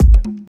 • Summer House Tuned Kick.wav
Summer_House_Tuned_Kick_rwv.wav